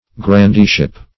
Search Result for " grandeeship" : The Collaborative International Dictionary of English v.0.48: Grandeeship \Gran*dee"ship\, n. The rank or estate of a grandee; lordship.